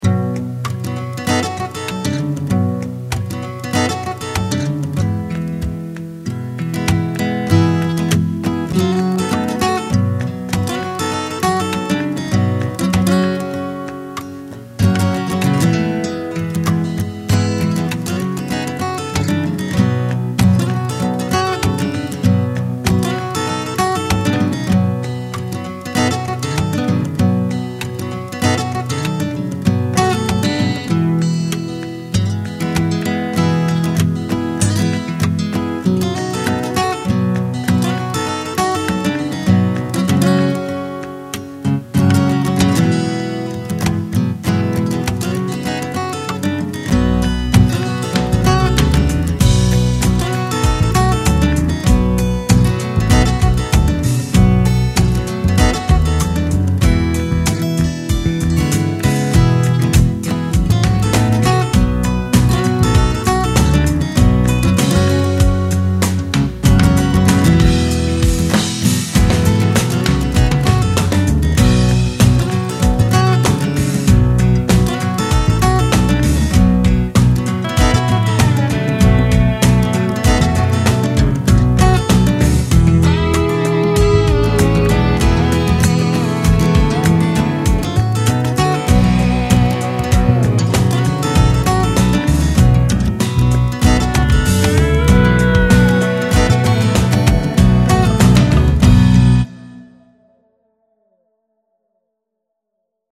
Largo [40-50] nostalgie - guitare acoustique - - -